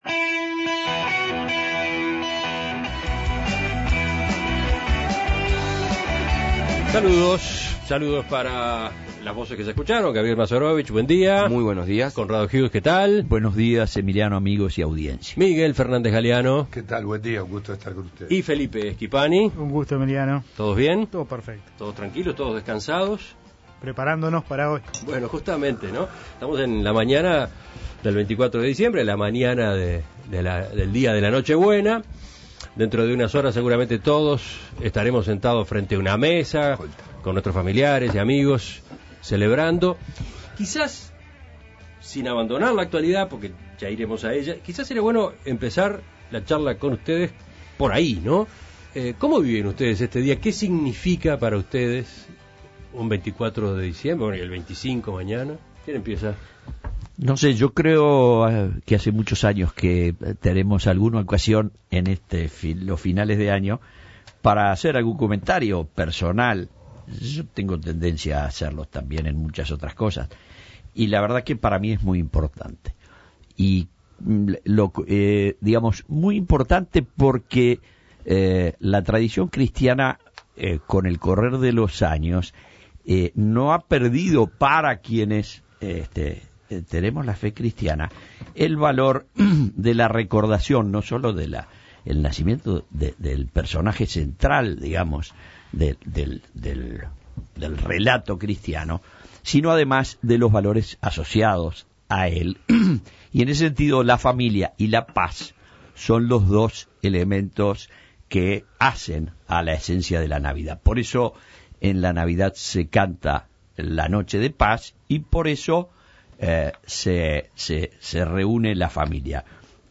La Tertulia ¿Cómo viven los contertulios la Noche Buena?